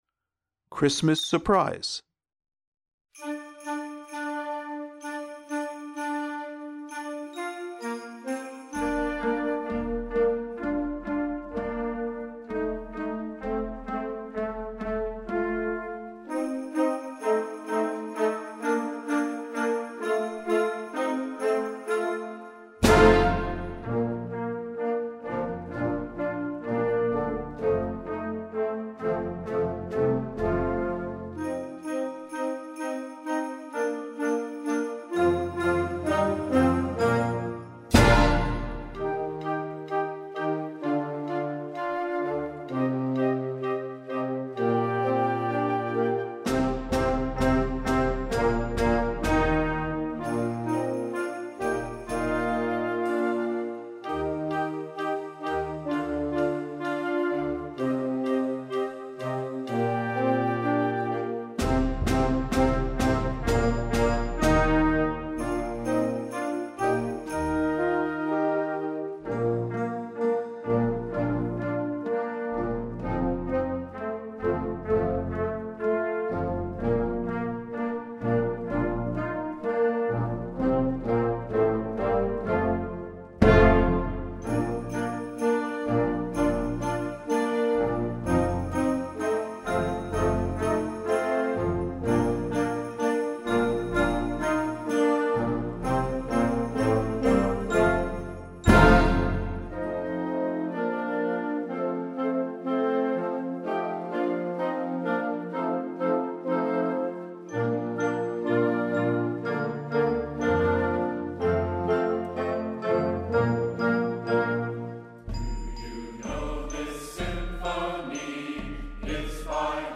Besetzung: Blasorchester
It also includes a unique section where the band sings.